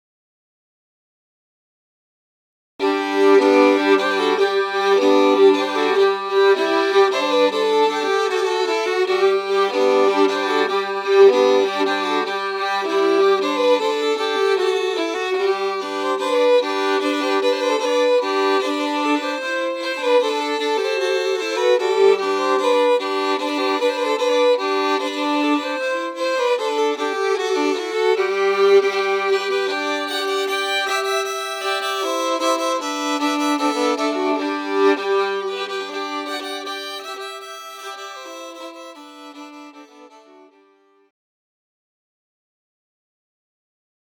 The next two tunes are examples of what we might play as pre-ceremony music:
ShortCoolShottish.mp3